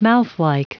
Prononciation du mot mouthlike en anglais (fichier audio)
Prononciation du mot : mouthlike